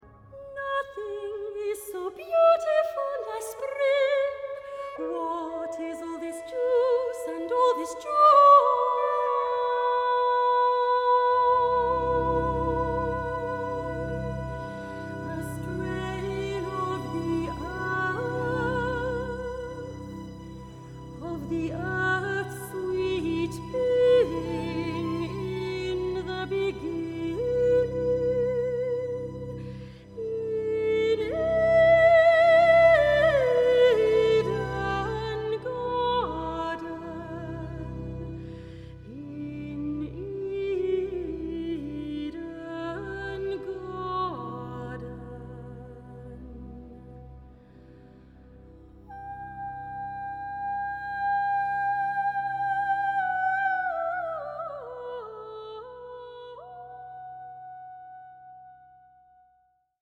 Choir & Baroque Orchestra